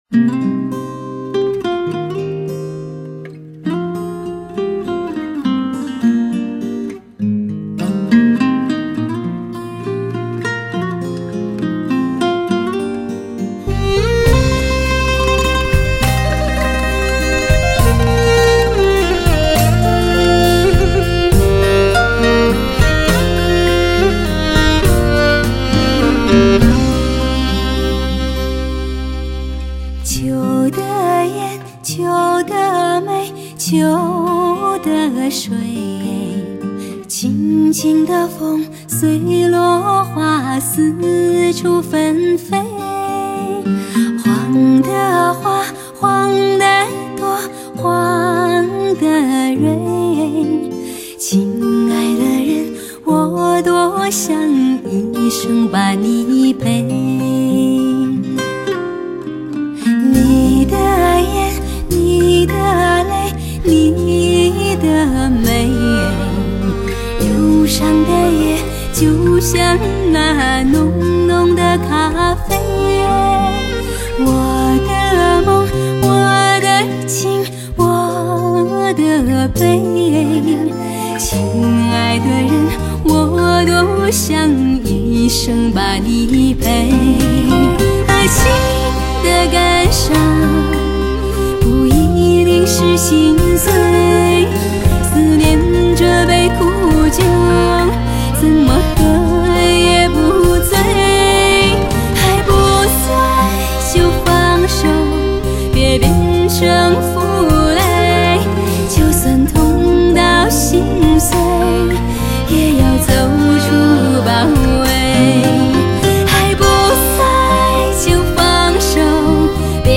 一首沐浴着异域风情的感伤情歌
她的嗓音自然且 真挚，率性且赤诚，被誉为是“草原天籁之音”。
吉他
马头琴